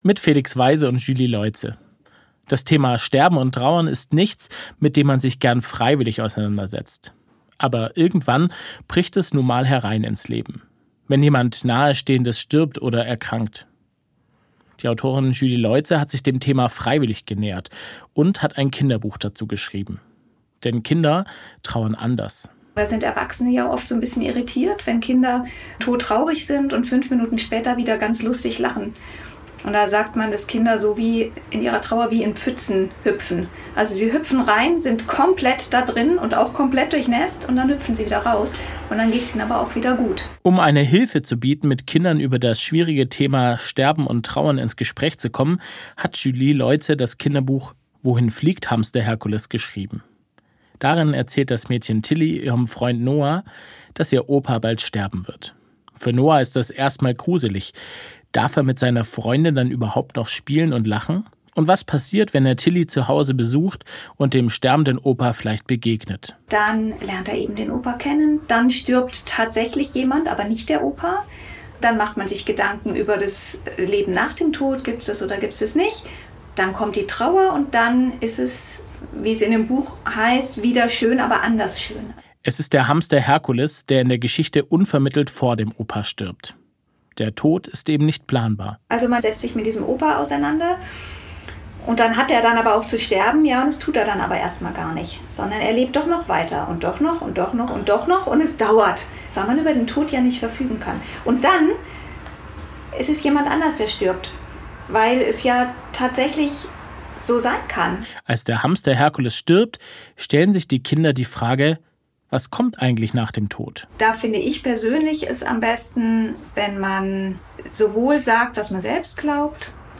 Das Interview wurde Ende Oktober im SWR Radio gesendet.